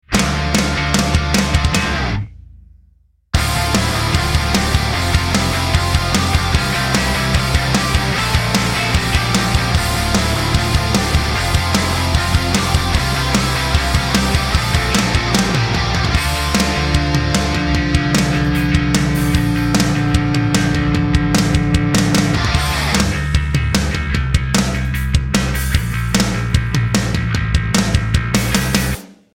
Snare Main (Song)